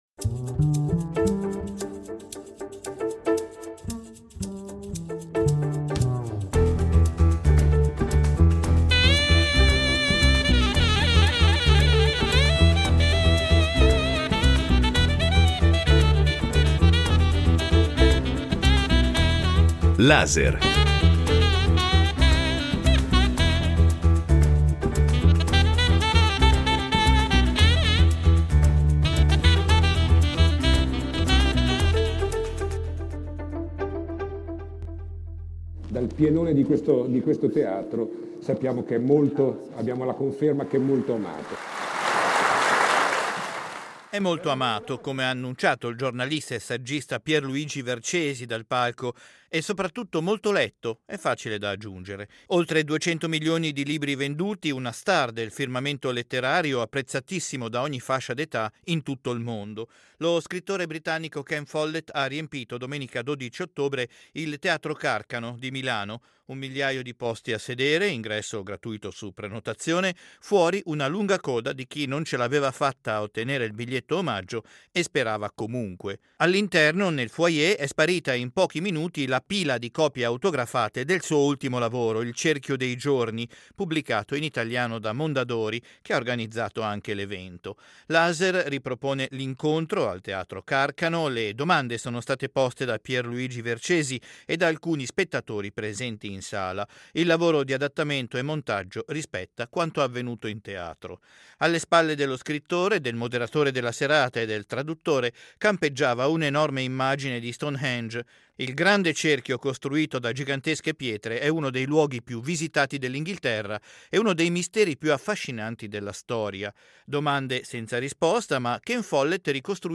Lo scrittore britannico Ken Follett ha tenuto domenica 12 ottobre un incontro pubblico al teatro Carcano di Milano.
Laser ha avuto la possibilità di registrare la serata.